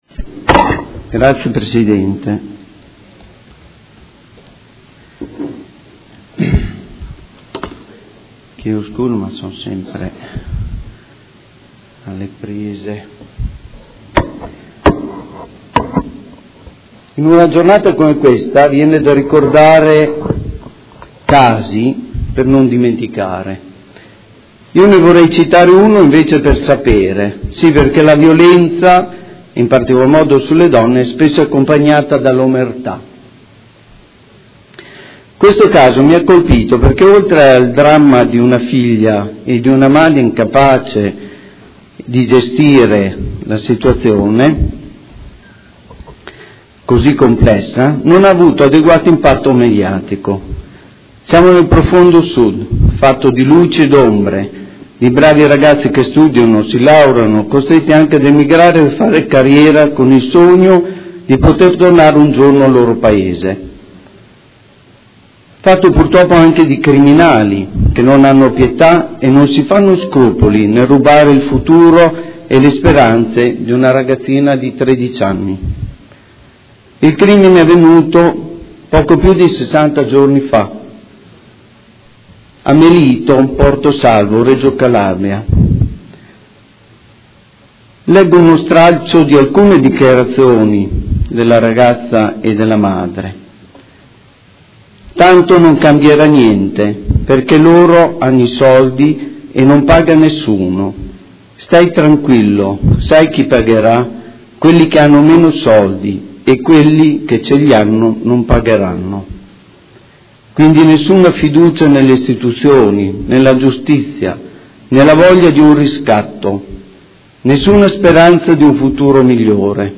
Seduta del 24/11/2016 Dibattito.